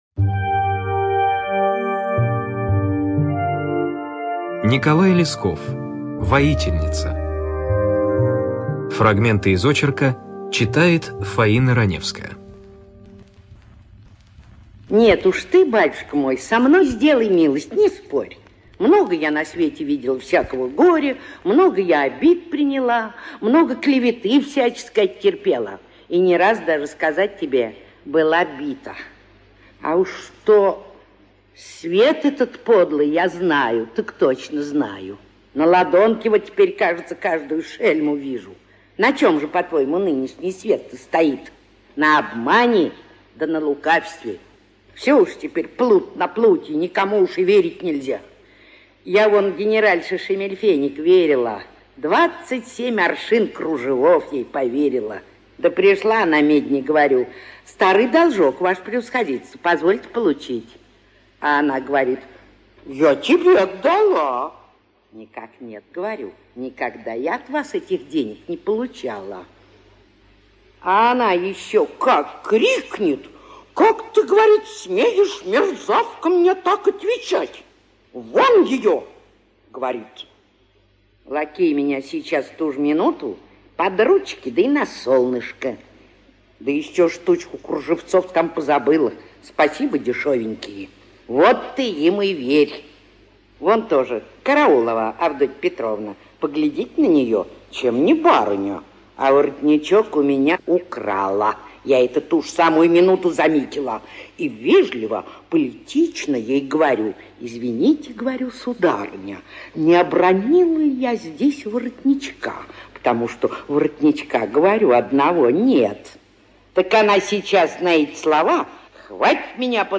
ЧитаетРаневская Ф.